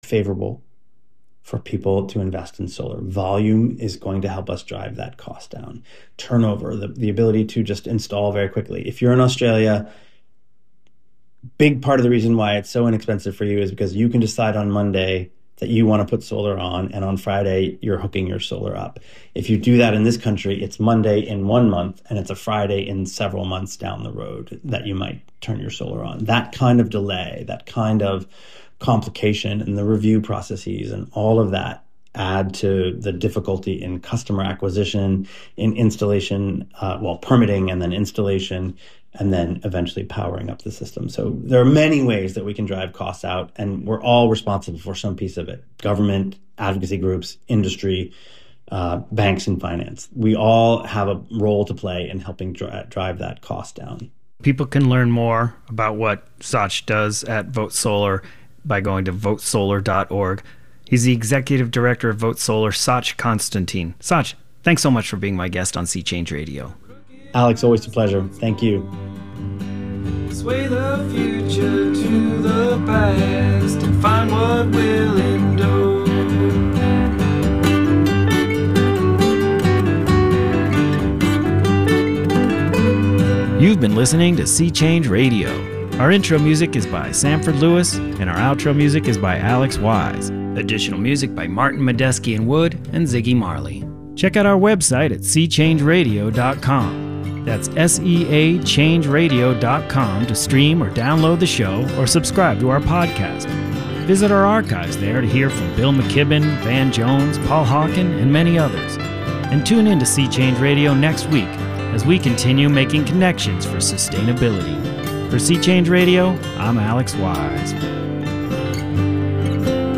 11:30am to 12:00pm A radio show about visual art.